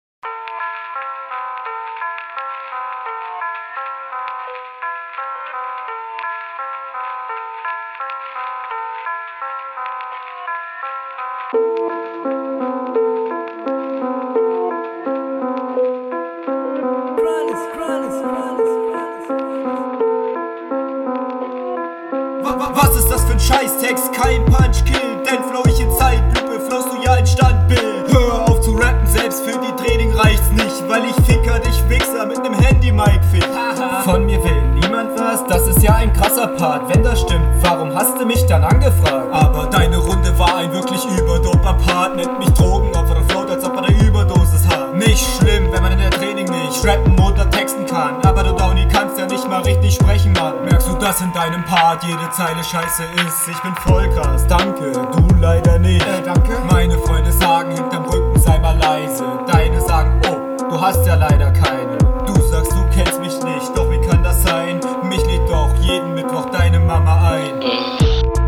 Dein Flow ist stabil, hier und da aber noch unroutiniert.
Qualiät auch hier nicht wirklich das gelbe vom Ei aber man versteht dich.